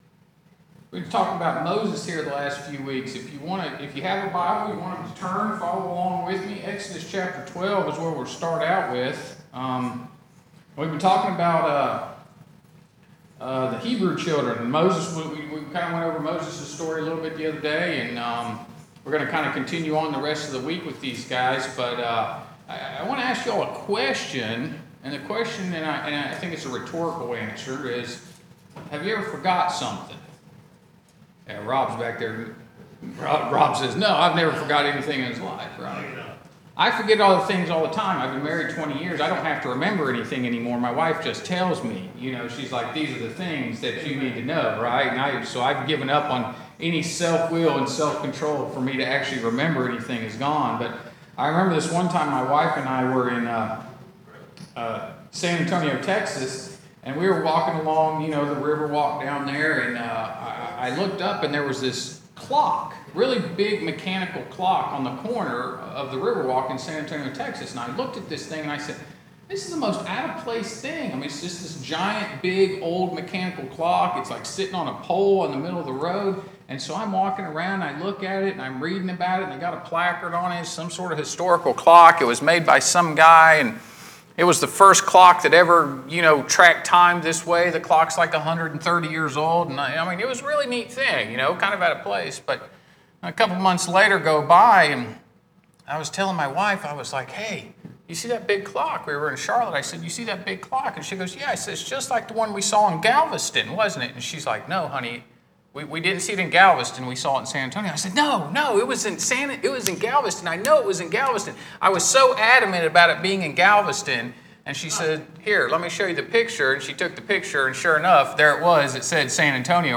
VBS Adult Devotional 3 – Bible Baptist Church